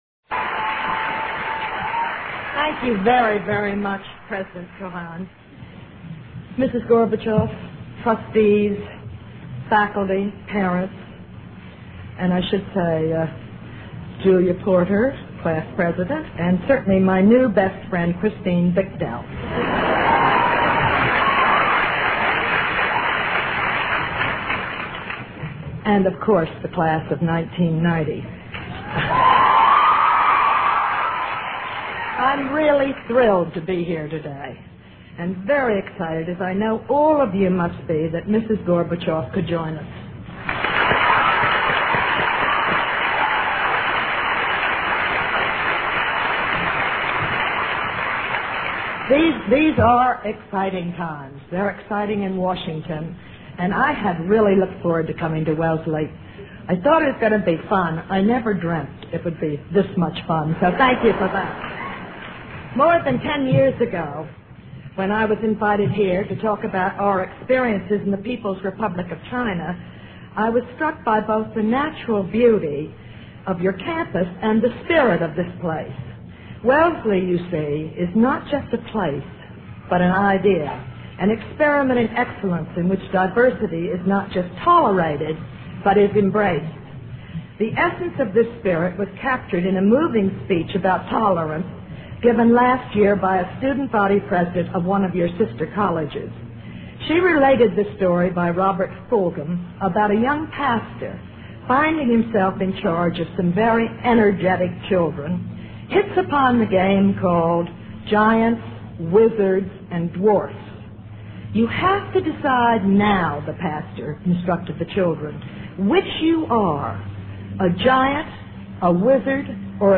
Commencement Address at Wellesley College